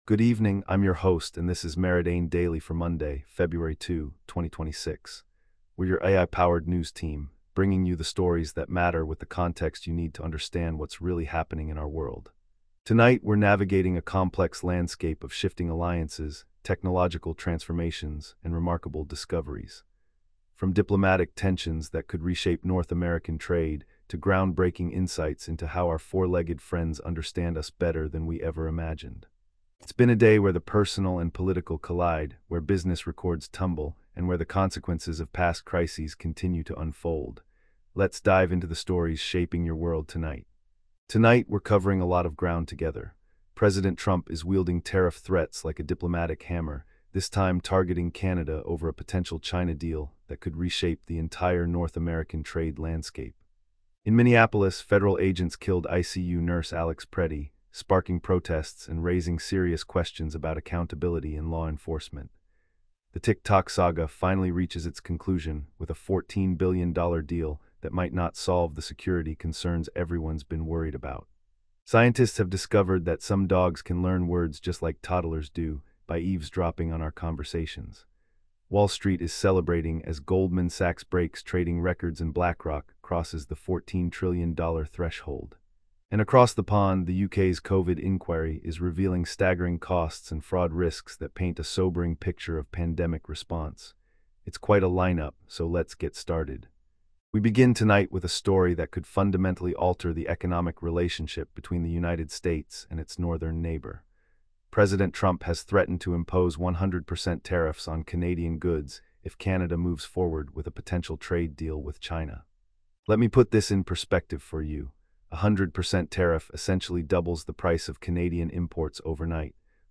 Your nightly AI-powered news briefing for Feb 2, 2026